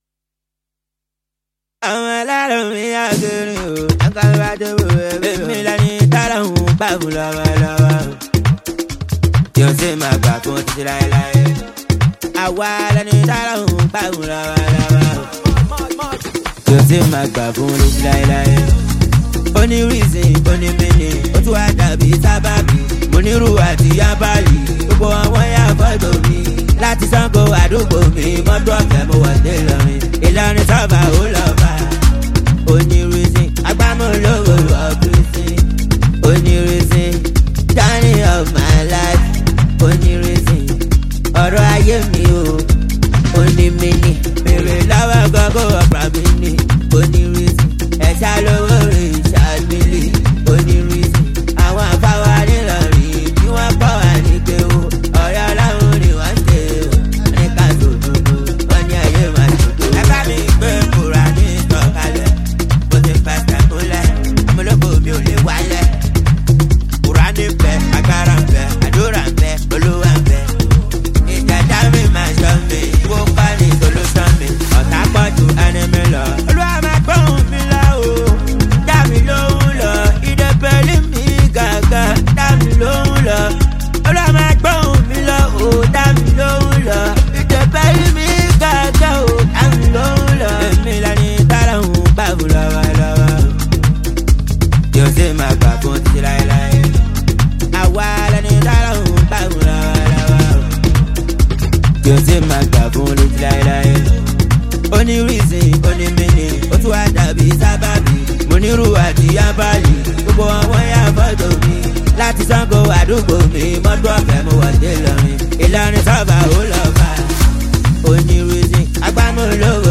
raw track